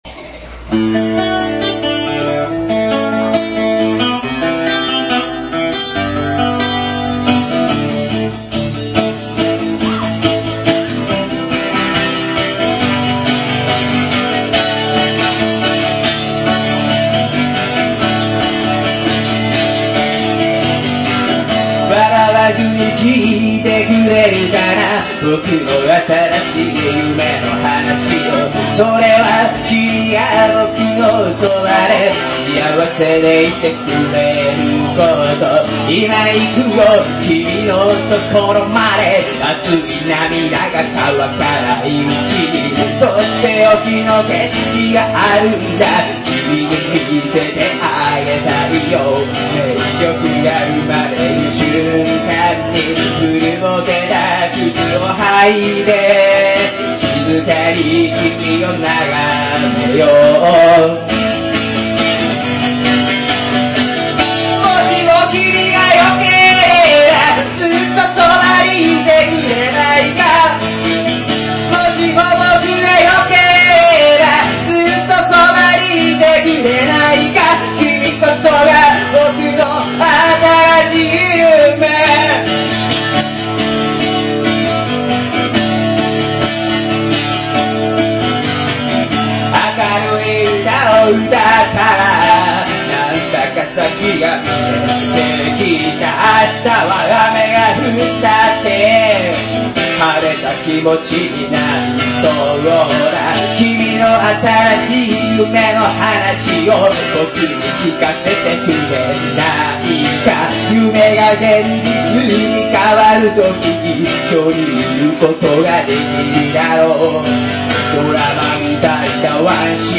ラブソングにしてしまった。
完成したその日に音源をUPしたが、LIVEで割と綺麗なものがとれたので、